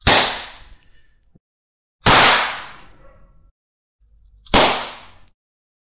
Звуки хлопушки
Несколько ярких выстрелов из хлопушки с конфетти